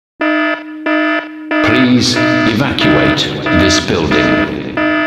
evacuate.wav